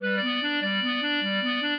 clarinet
minuet12-5.wav